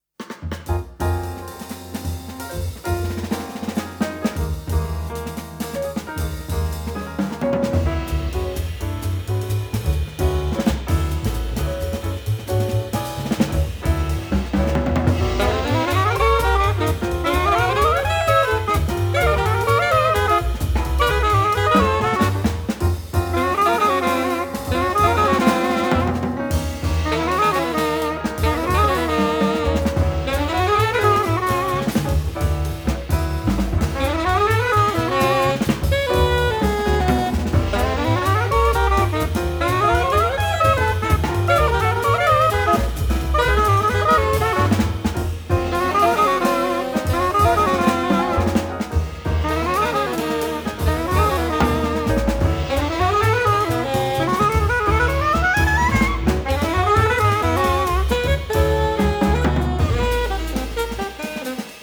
The Best In British Jazz
Recorded at Fish Factory Studio, London 2016